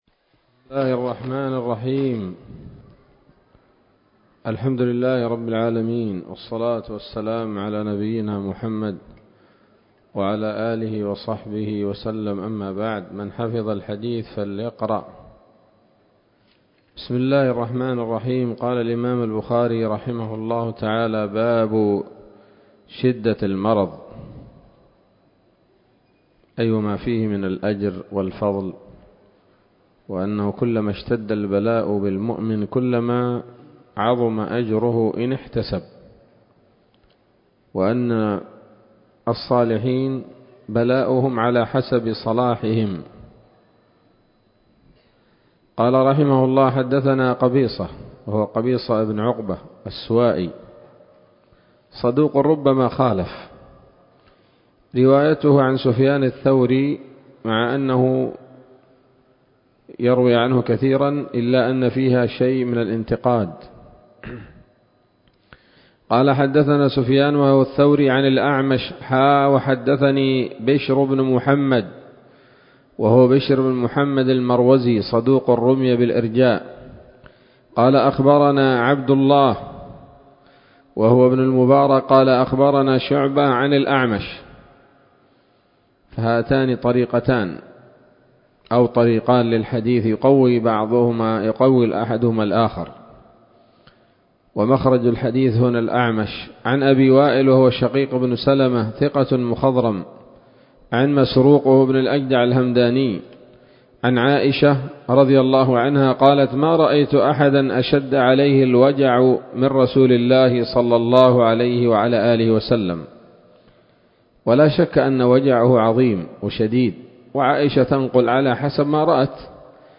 الدرس الثاني من كتاب المرضى من صحيح الإمام البخاري